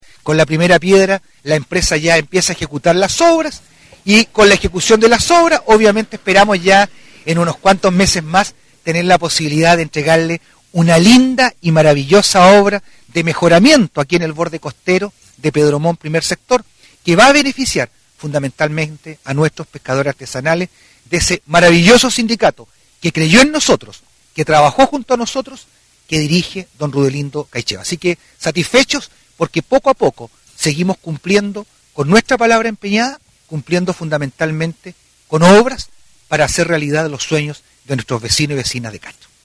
cuña-2-alcalde-vera-tema-primera-piedra.mp3